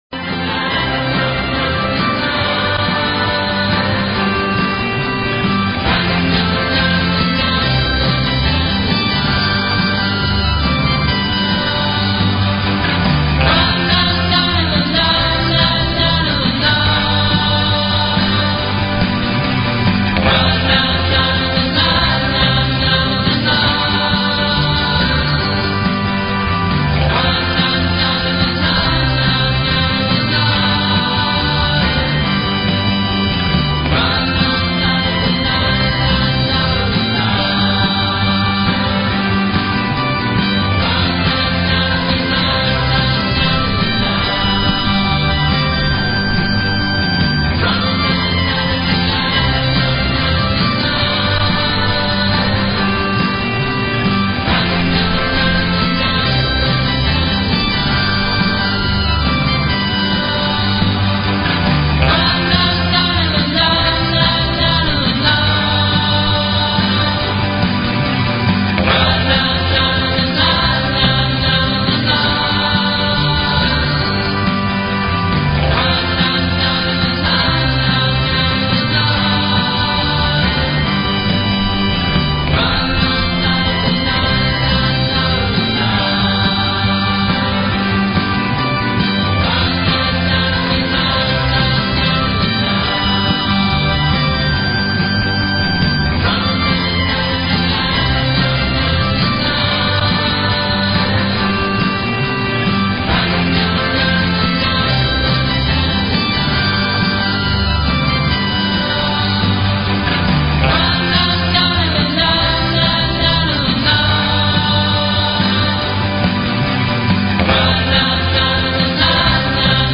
Talk Show Episode, Audio Podcast, The_Freedom_Message and Courtesy of BBS Radio on , show guests , about , categorized as
It's a live internet radio call in show for and about the Ron Paul Revolution.